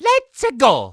Mario cheers for the player. From Mario Golf: Toadstool Tour.
Mario_(Let's-a_go)_-_Mario_Golf-_Toadstool_Tour.oga